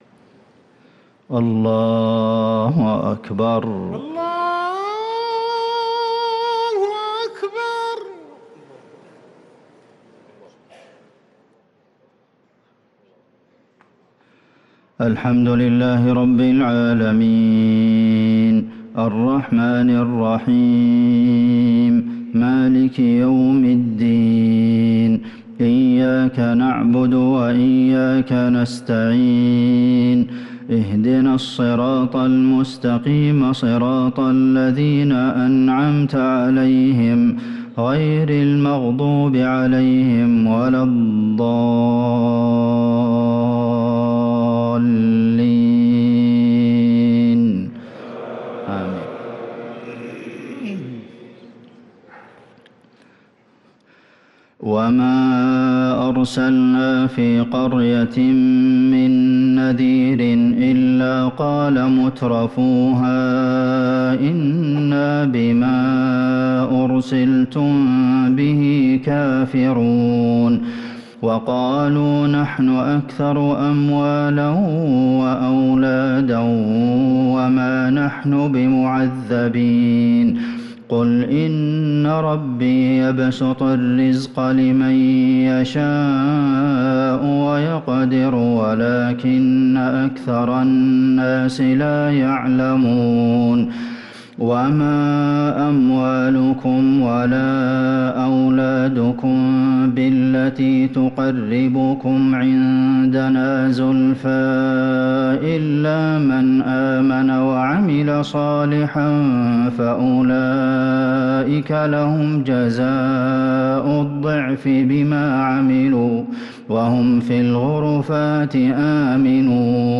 صلاة العشاء للقارئ عبدالمحسن القاسم 12 ربيع الأول 1445 هـ
تِلَاوَات الْحَرَمَيْن .